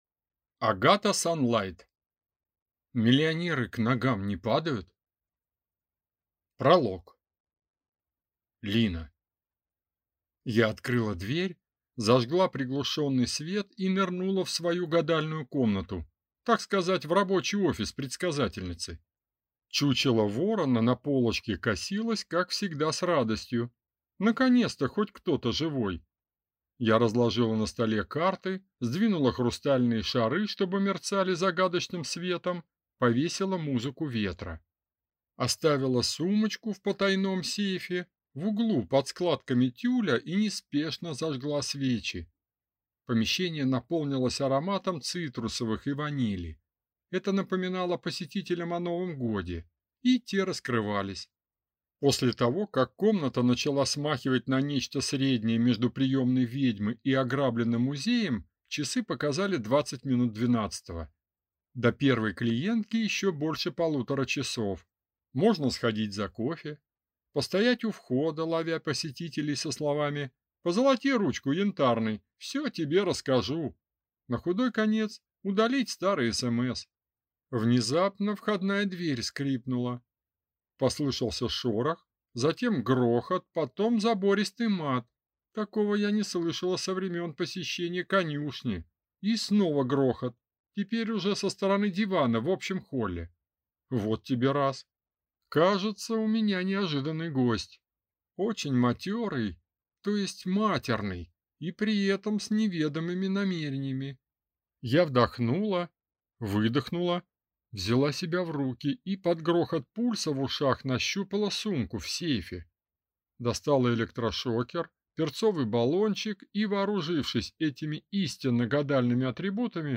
Аудиокнига Миллионеры к ногам не падают?